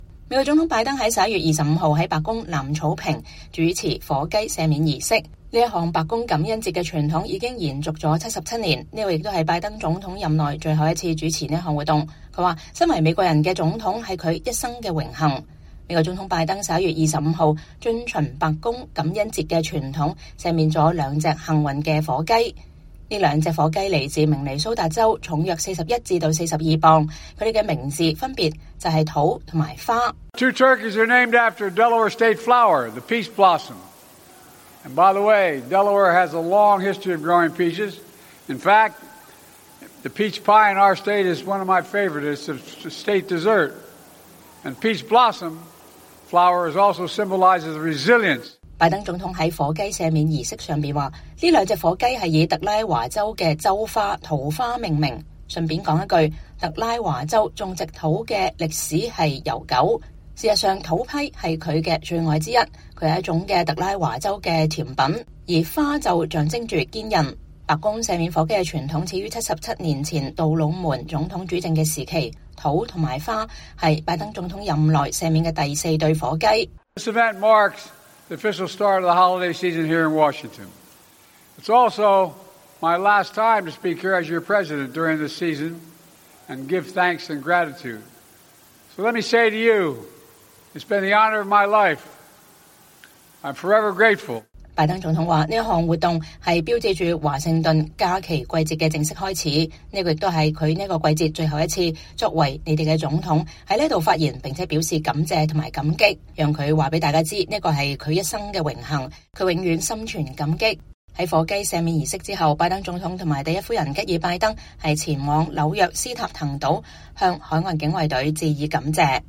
美國總統拜登11月25日在白宮南草坪主持“火雞赦免儀式”，這項白宮感恩節傳統已經延續了77年。